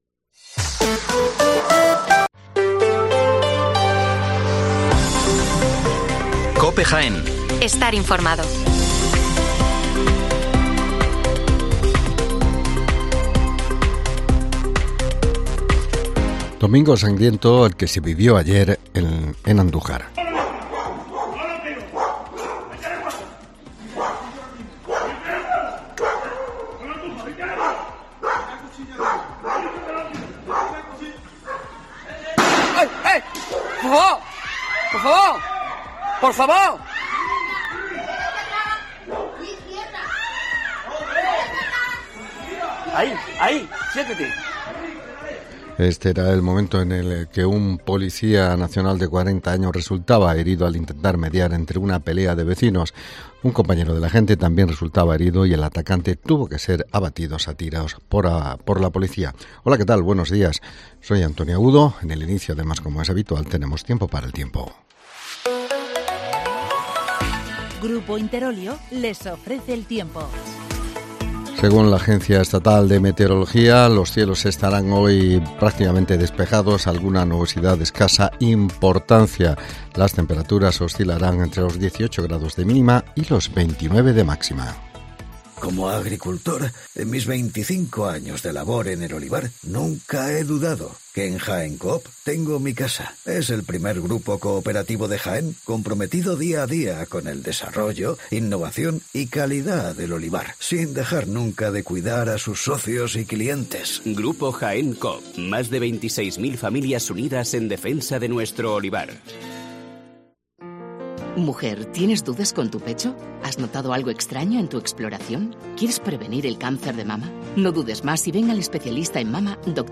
Las noticias locales de las 7'55 horas del 12 de junio de 2023